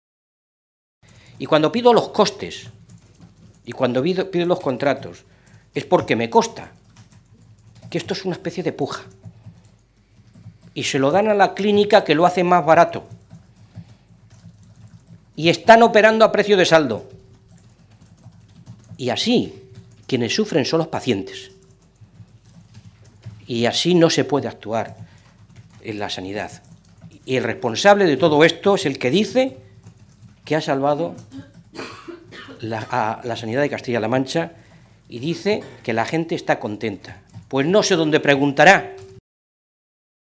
Se pronunciaba de esta manera Mora esta mañana, en Toledo, en una comparecencia ante los medios de comunicación en la que se hacía eco de la última de esas denuncias.
Cortes de audio de la rueda de prensa